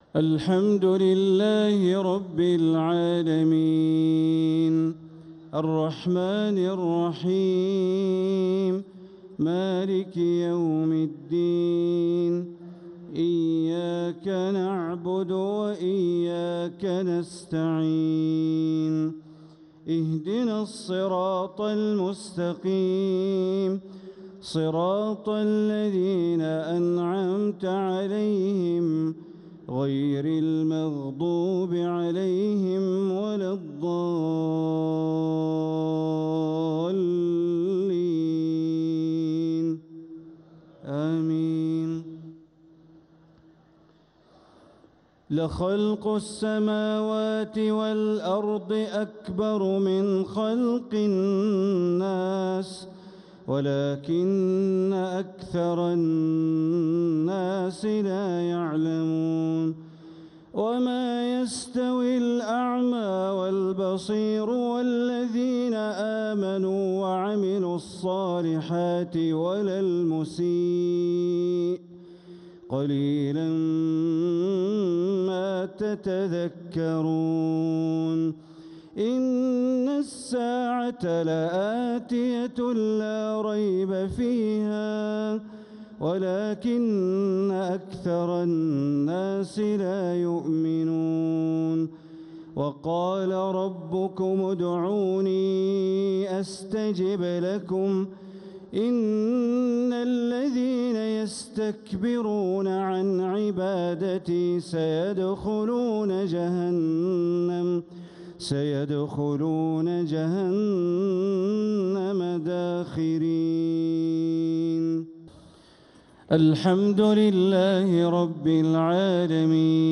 (وقال ربكم ادعوني أستجب لكم) عشائية عذبة من سورة غافر 57-63 | ٣ رمضان ١٤٤٧هـ > 1447هـ > الفروض - تلاوات بندر بليلة